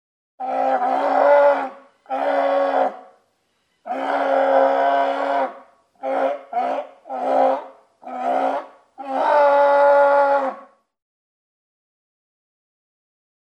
На этой странице собраны разнообразные звуки морских львов – от их громкого рычания до игривого плеска в воде.
Рычание морского льва